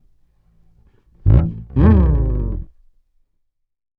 PHPH_bass_slide_04_120.wav